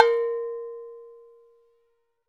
Index of /90_sSampleCDs/NorthStar - Global Instruments VOL-2/CMB_CwBell+Agogo/CMB_CwBell+Agogo